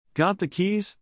“got the keys” debated by the characters. Monica uses rising intonation to prove it was said as the question